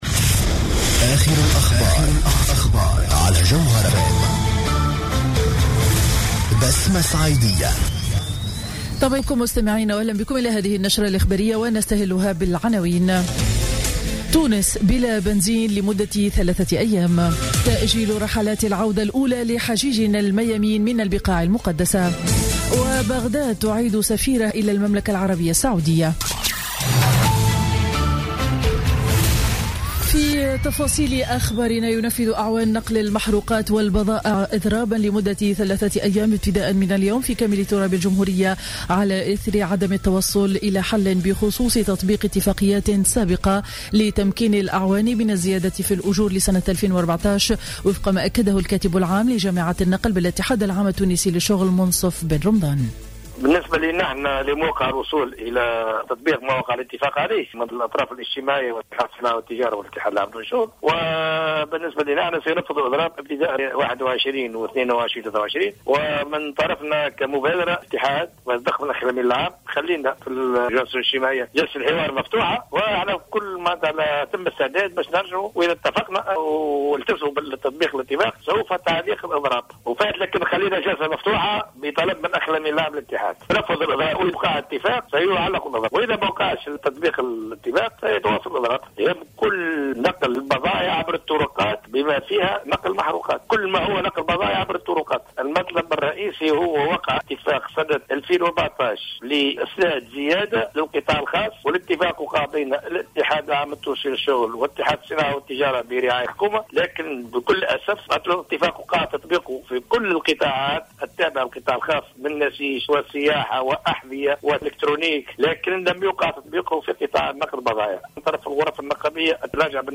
نشرة أخبار السابعة صباحا ليوم الاثنين 21 سبتمبر 2015